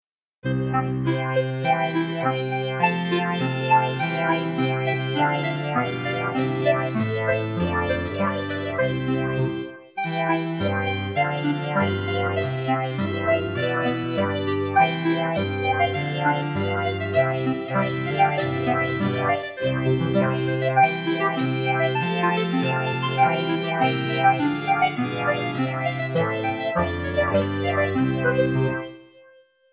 Playing around with my MIDI piano is fun - but listening to it could be a pain.
, a variation on the song »Ah, vous dirai-je Maman« (Morgen kommt der Weihnachtsmann) by Wolfgang Amadeus Mozart (KV 265), and decide yourself.